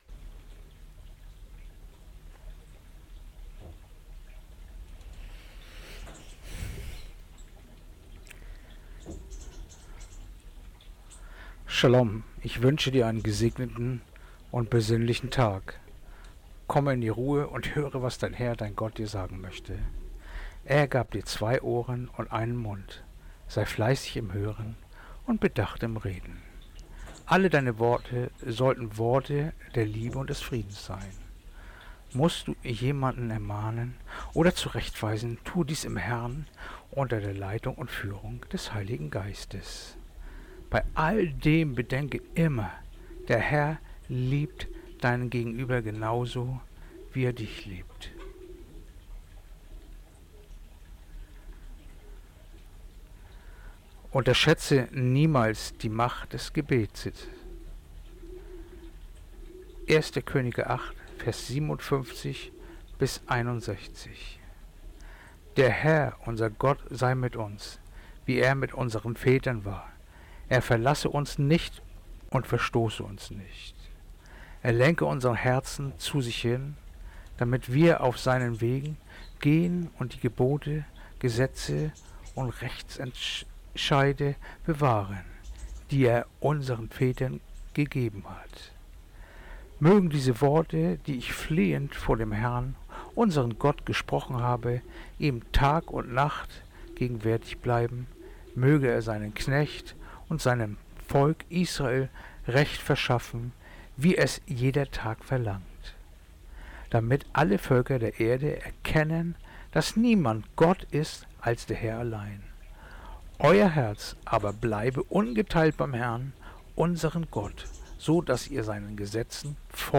Andacht-vom-28-Oktober-1.Koenige-8-57-61